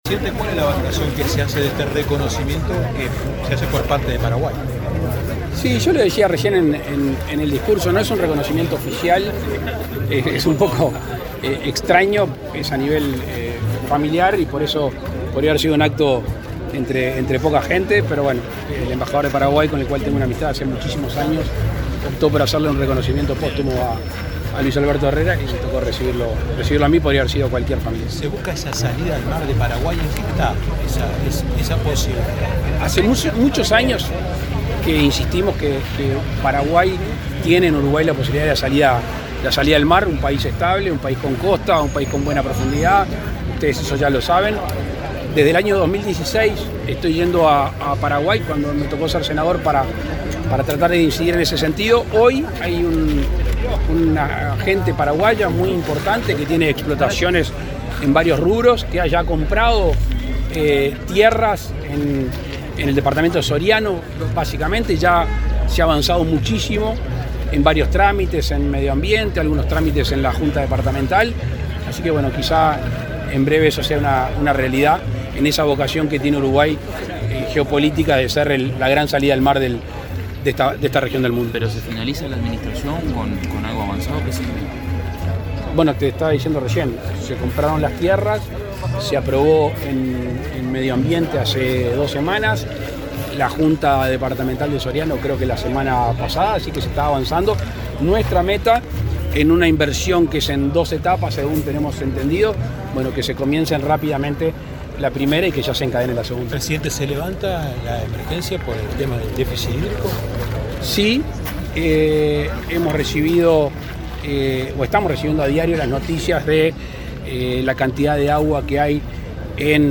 Declaraciones del presidente Lacalle Pou a la prensa
El presidente de la República, Luis Lacalle Pou, dialogó con la prensa este miércoles 23 en Montevideo e informó sobre el fin de la emergencia hídrica